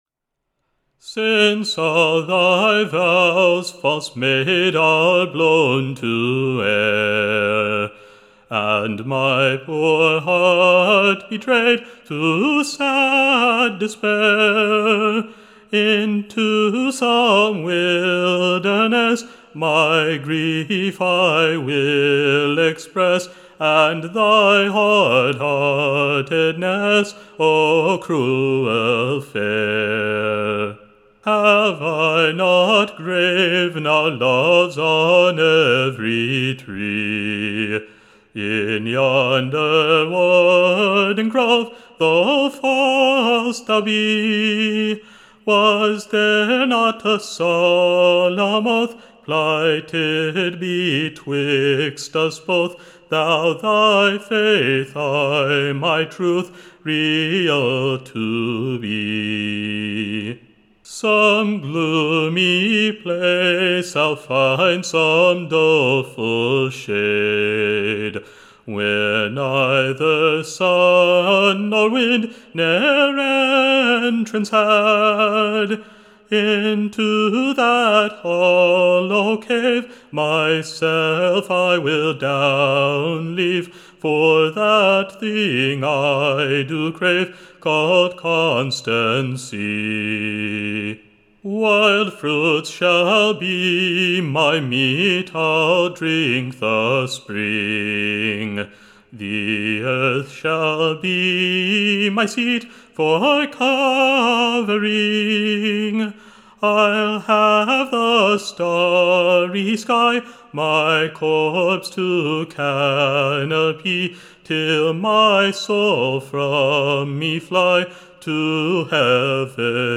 Recording Information Ballad Title CROMLET's LILT, Tune Imprint To be sung with its own proper Tune: Standard Tune Title Cromlet's Lilt Media Listen 00 : 00 | 15 : 38 Download Ry1.58-1.mp3 (Right click, Save As)